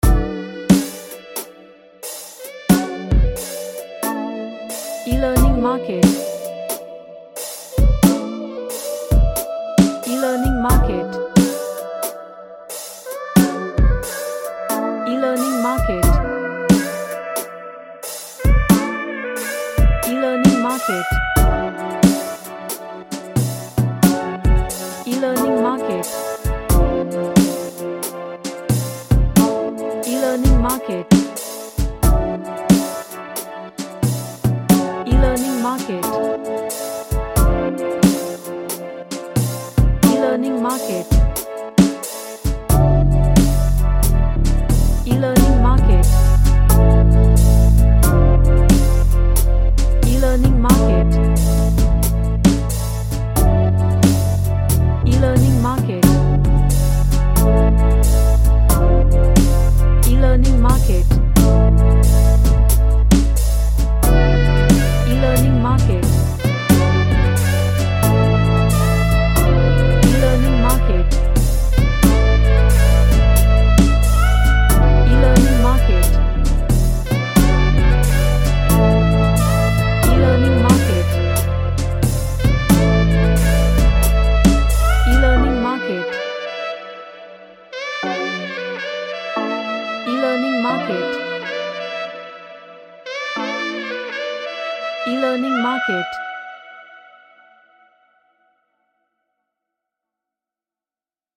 A sexy night jazz music
Sexy / Sensual